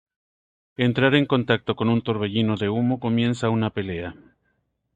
Pronounced as (IPA) /peˈlea/